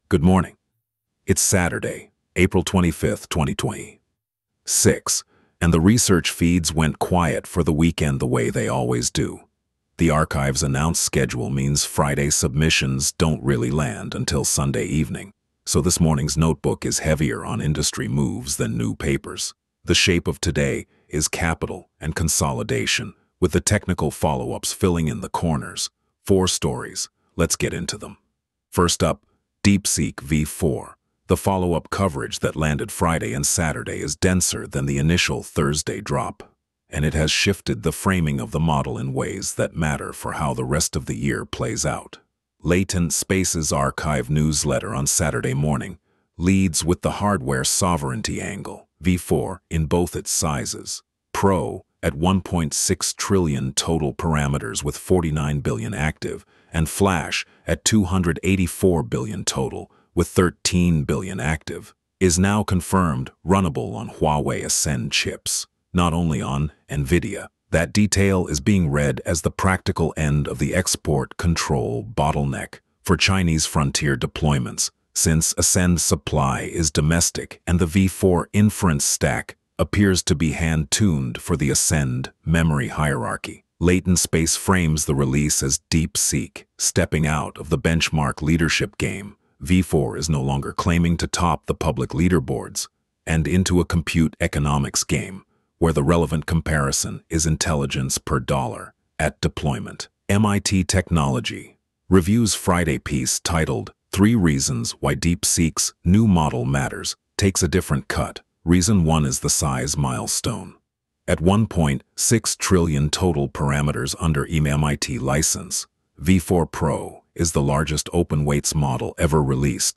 Wolf Digest — 2026-04-25 Coverage window: 2026-04-24 03:26 ET → 2026-04-25 07:09 ET ▶ Press play to listen Saturday, April 25, 2026 13m 50s · top-4 narrated briefing Subscribe Apple Podcasts Spotify Download MP3